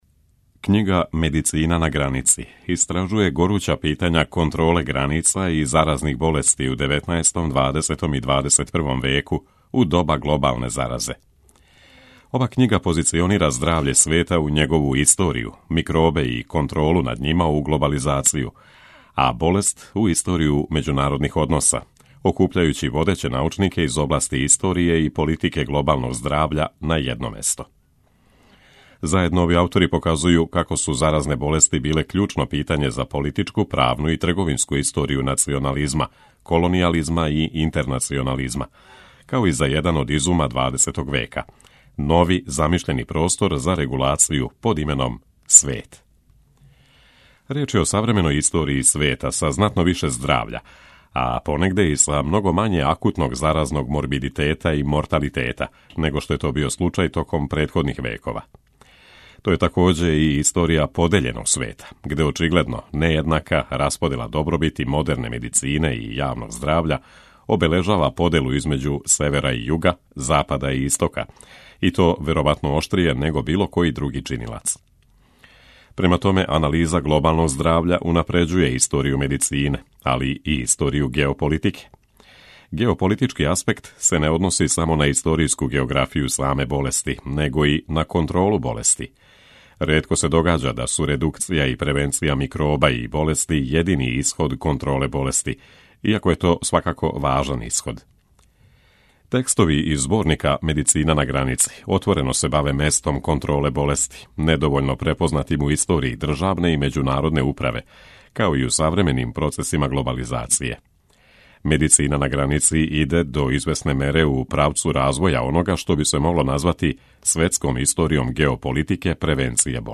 У емисијама РЕФЛЕКСИЈЕ читамо есеје или научне чланке домаћих и страних аутора.